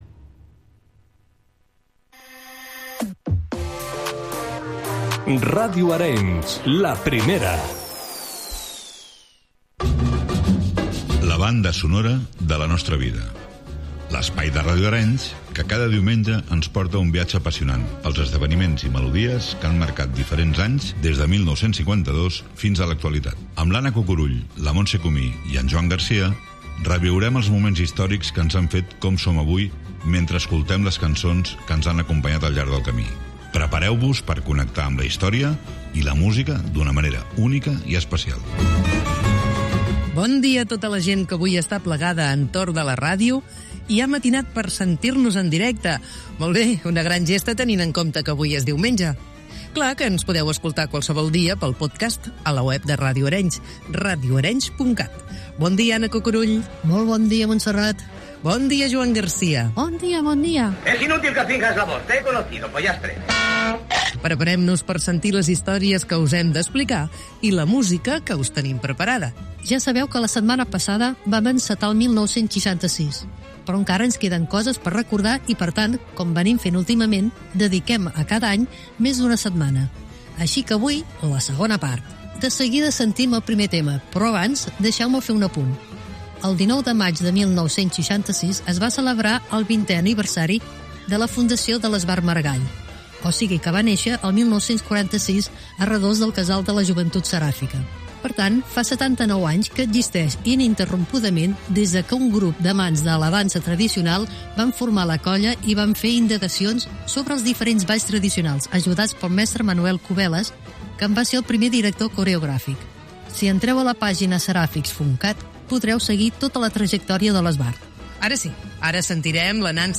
1 Entrevista Conseller Lluís Puig 1:00:00